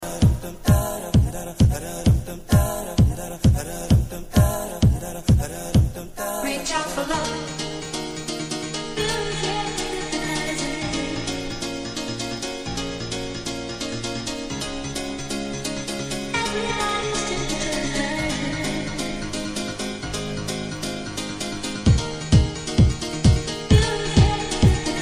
temperature rising italo mix ????